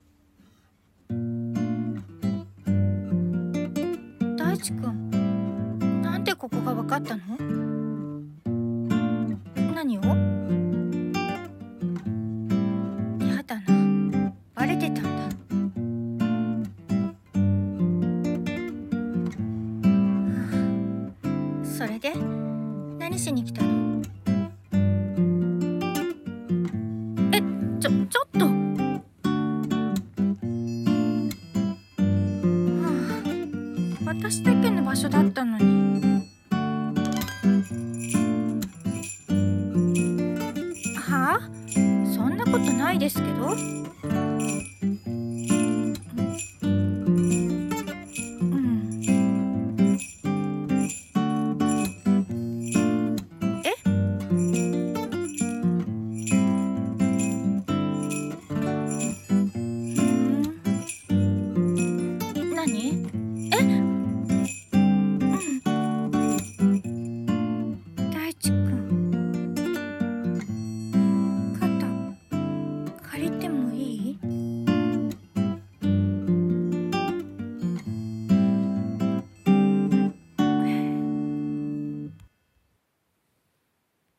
【恋愛声劇】ふたりの居場所【2人台本】